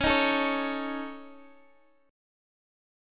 C#sus2#5 chord